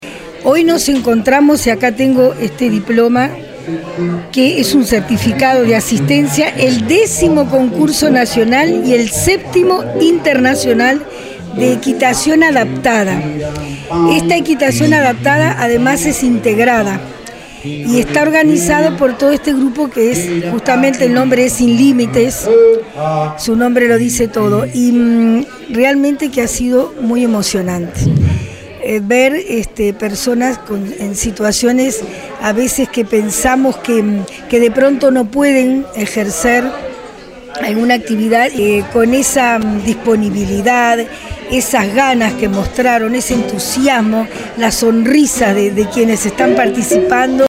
Por su parte, la Alcaldesa de Ciudad de la Costa, Sonia Misirián, destacó que se trató del décimo concurso nacional y el séptimo internacional de equitación adaptada e integrada.
hipico_sonia_misirian_alcaldesa_ciudad_de_la_costa.mp3